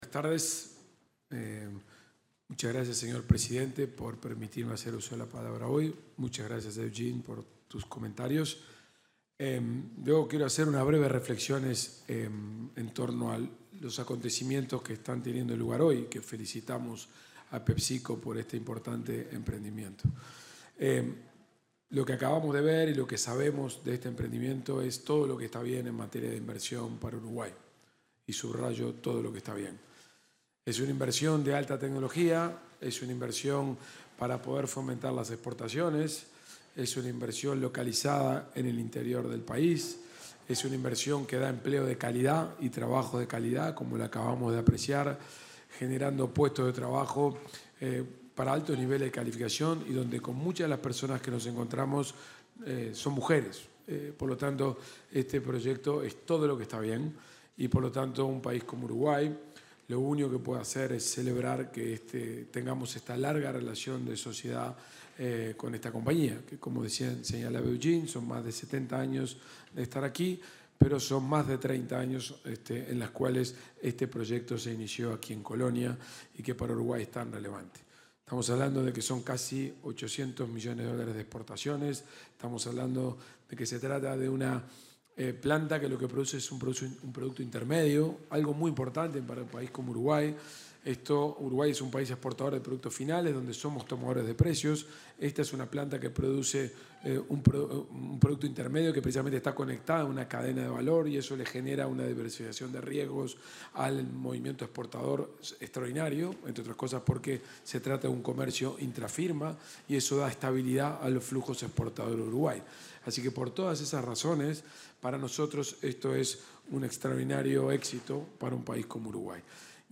Palabras del ministro de Economía, Gabriel Oddone
El ministro de Economía y Finanzas, Gabriel Oddone, se expresó durante la inauguración de una planta de almacenamiento de la compañía global de